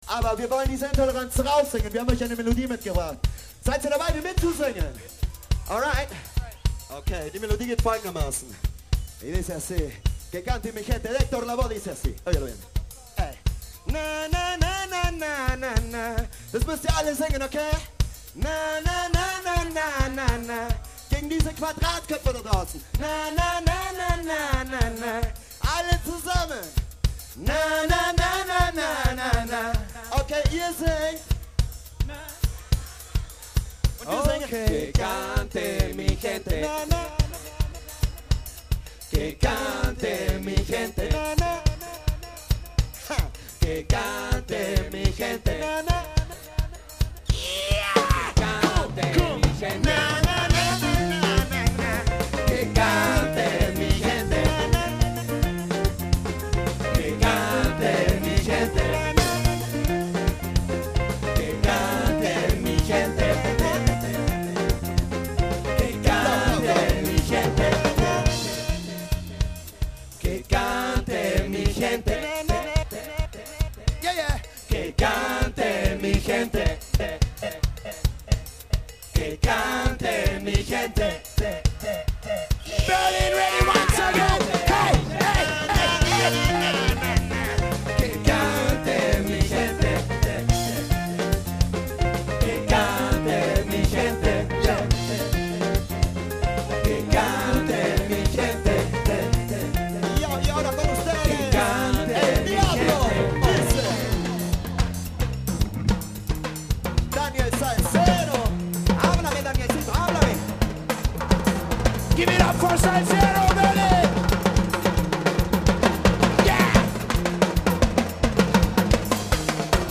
Audio der Hauptbühne
Reggae)Soundcheck 2 MBMAGNET